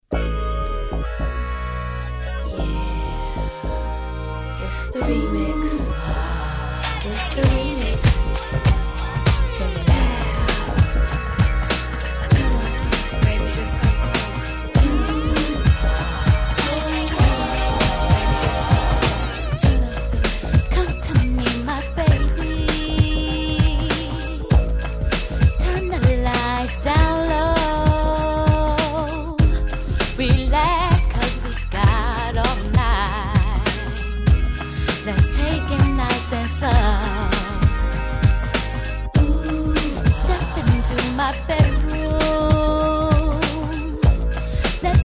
【Condition】B (A-1にプチノイズの出る傷箇所あり。針飛びはございません。DJ Play可。)
試聴ファイルは別の盤から録音してあります。)